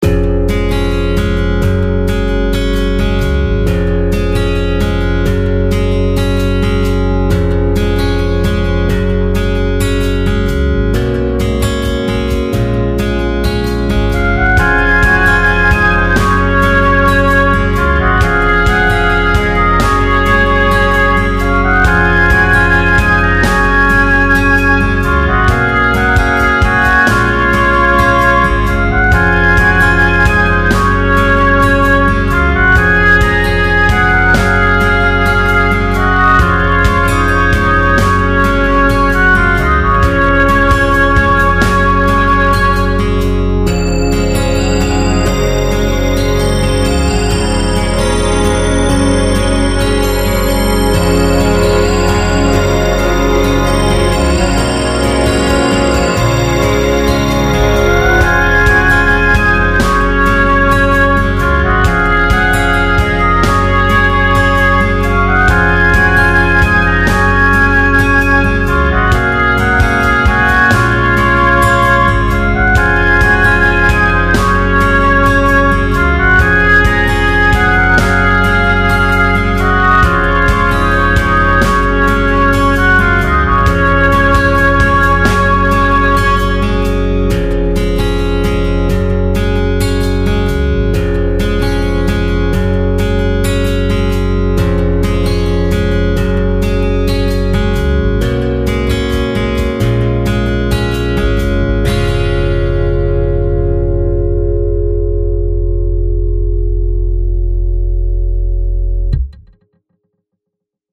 昨日作った曲に、対旋律を加えて全体の音源を変えたり音を弄ったりした。